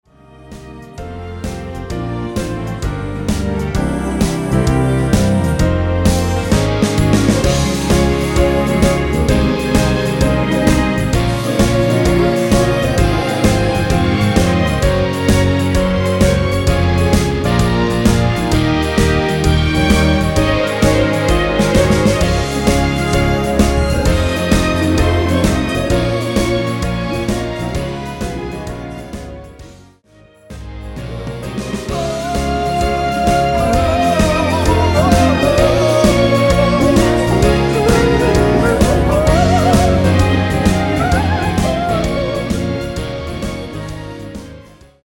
전주 없는 곡이라 전주 2마디 만들어 놓았습니다.
엔딩이 페이드 아웃이라 라이브 하시기 편하게 엔딩을 만들어 놓았습니다.
Bb
앞부분30초, 뒷부분30초씩 편집해서 올려 드리고 있습니다.